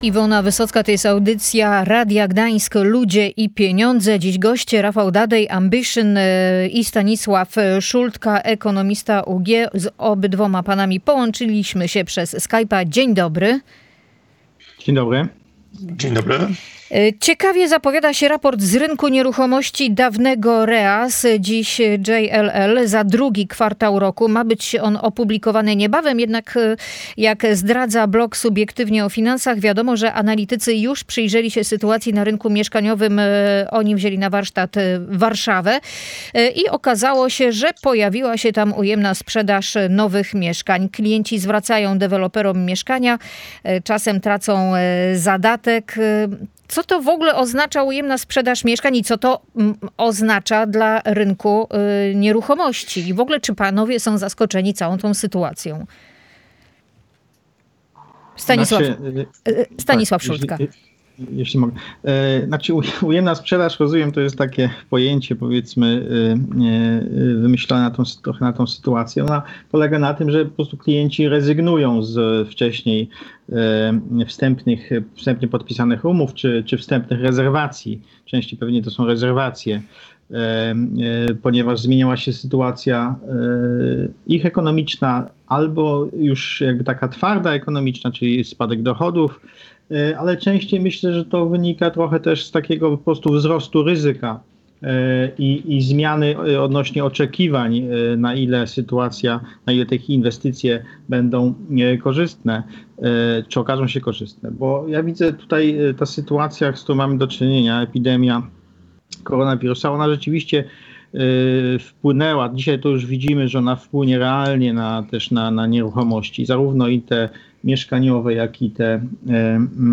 Te dwa zagadnienia omówiono w środowej audycji „Ludzie i Pieniądze”.